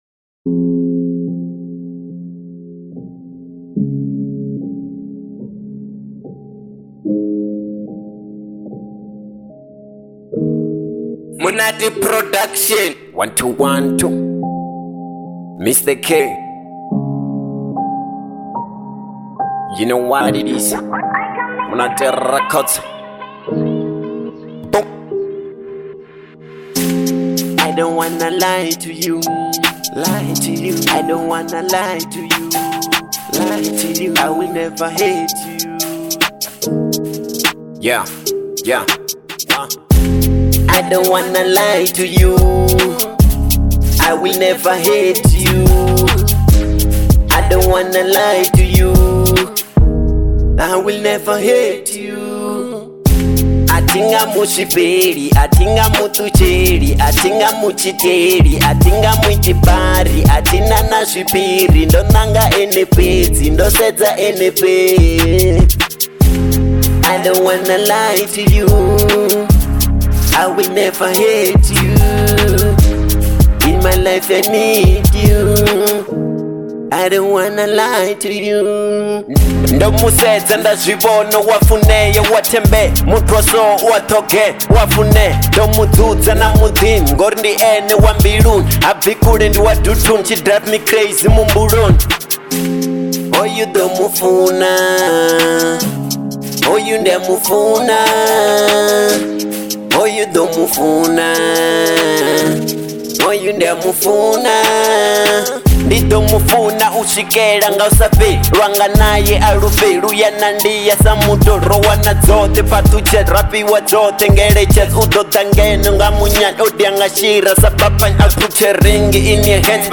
03:47 Genre : Venrap Size